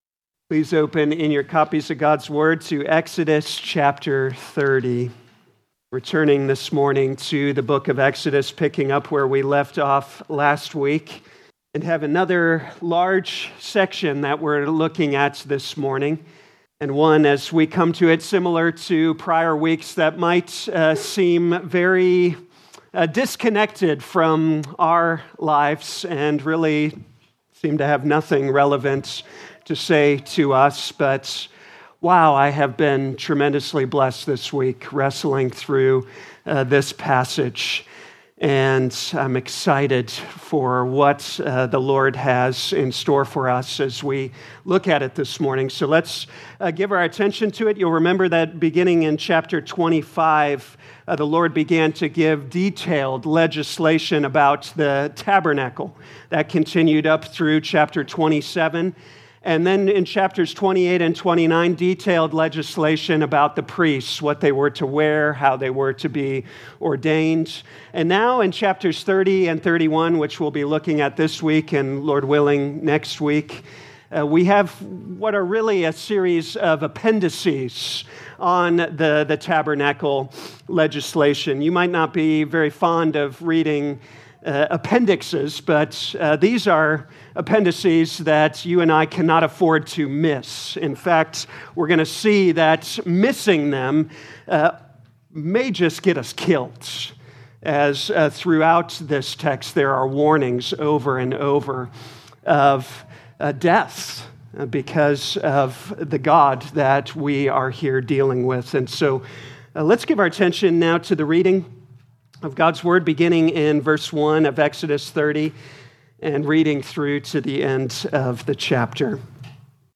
Audio Notes Bulletin All sermons are copyright by this church or the speaker indicated.